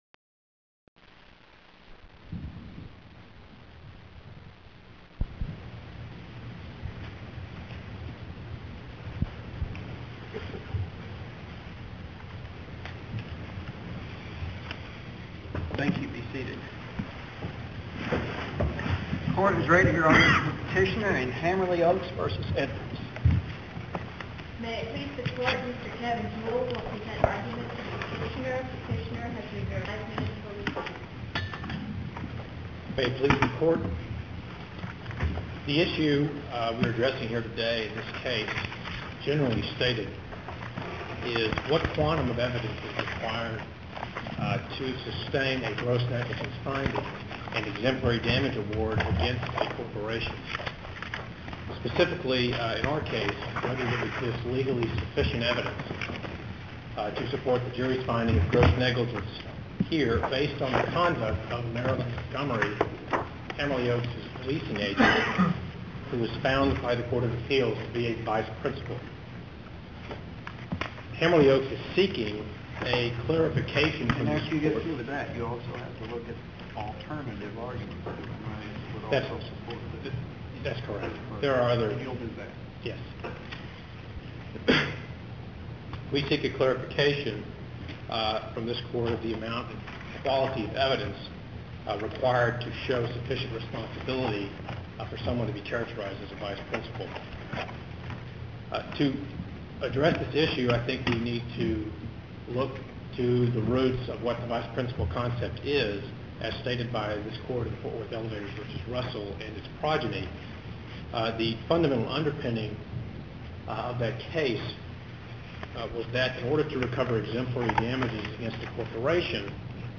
TJB | SC | Oral Arguments | Archived Recordings | By Year Argued | 1990-1999 | 1997
Case No. 96-0425 Oral Arguments Audio (MP3)